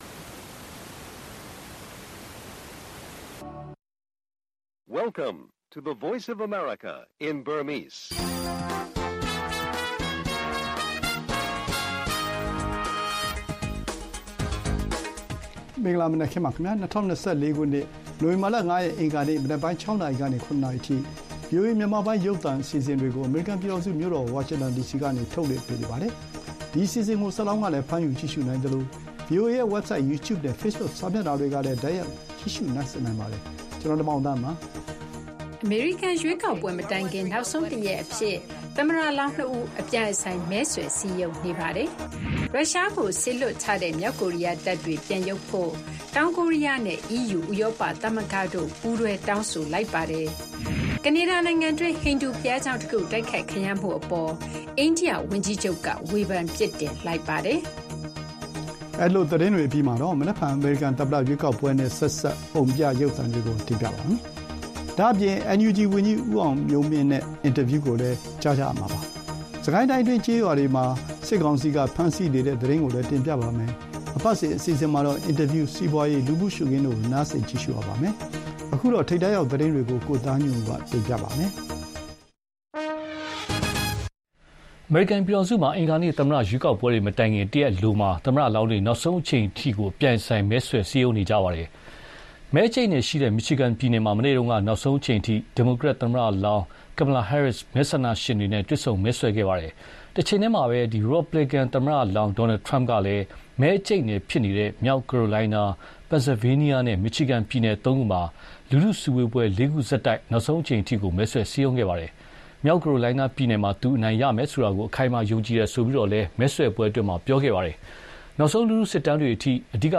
ရွေးကောက်ပွဲအကြိုနေ့ သမ္မတလောင်းတွေ အကျိတ်အနယ်မဲဆွယ်၊ ကနေဒါနိုင်ငံတွင်း ဟိန္ဒြူဘုရားကျောင်းတိုက်ခိုက်ခံရမှ အိန္ဒိယဝန်ကြီးချုပ်ရှုတ်ချ၊ အမေရိကန်ရွေးကောက်ပွဲနဲ့ မြန်မာ့လူ့အခွင့်အရေး အလားအလာ မေးမြန်းဆွေးနွေးချက် စတဲ့သတင်းတွေနဲ့ အပတ်စဉ်အစီအစဉ်တွေ ထုတ်လွှင့်ပေးပါမယ်။